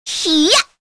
Shamilla-Vox_Attack2_kr.wav